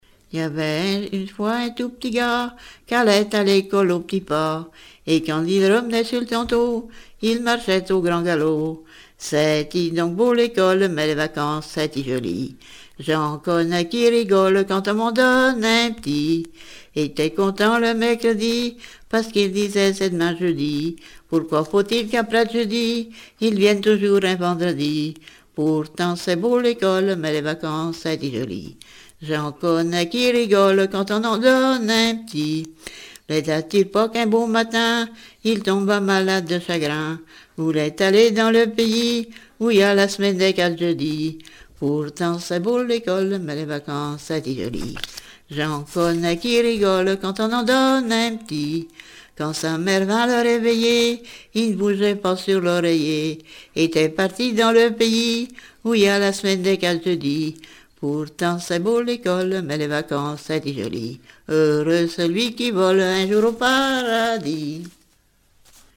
Mémoires et Patrimoines vivants - RaddO est une base de données d'archives iconographiques et sonores.
Plaine vendéenne
Genre strophique